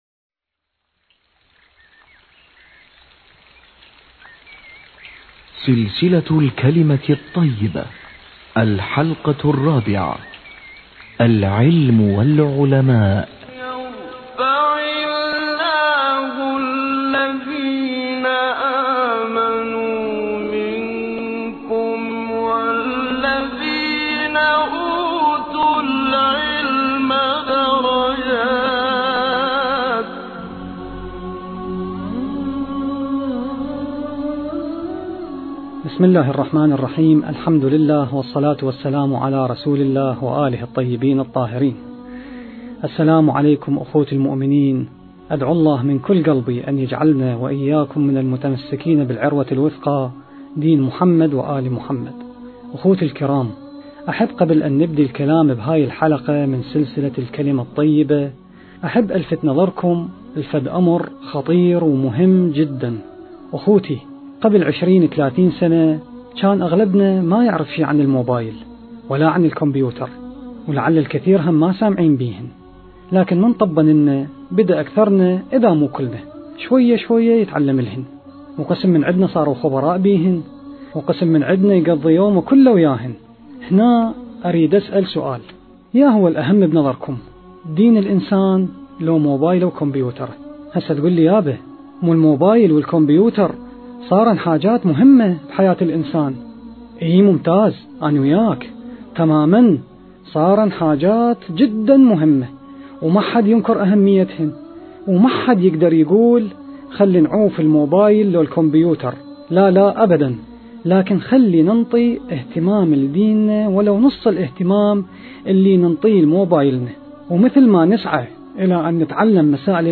محاضرات الرد على دعوى اليماني